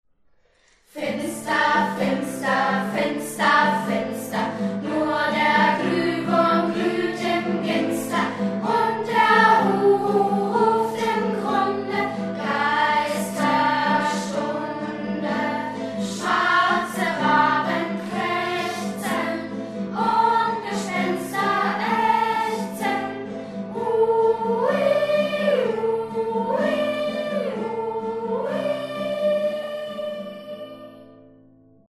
Das Lied wurde von einer 6. Klasse der Freien Waldorfschule Freiburg St. Georgen eingesungen.
Lied im Kanon Update needed